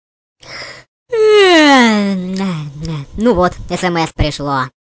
/64kbps) Описание: В зевательном варианте!!!